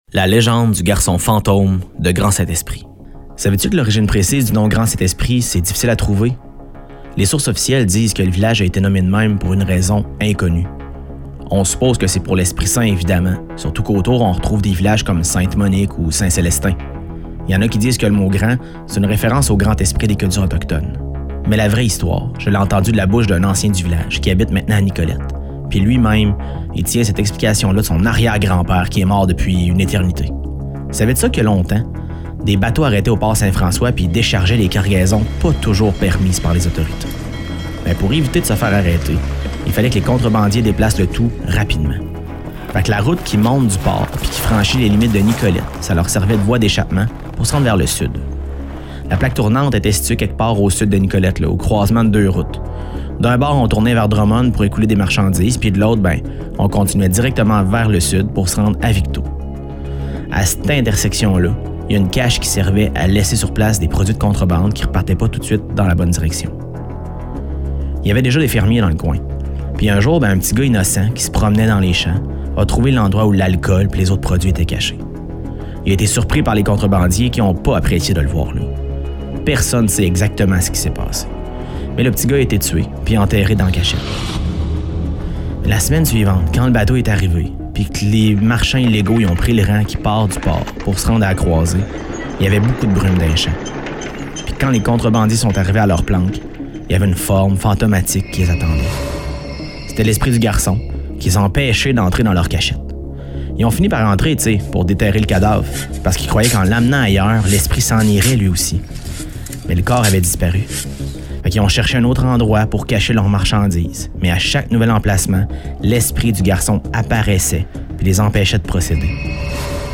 Ce conte est tiré d’une série de 17 constituant l’un des deux volets d’un projet initié par la MRC de Nicolet-Yamaska, grâce au financement du ministère de la Culture et des Communications du Québec, dans le cadre de l’Entente de développement culturel.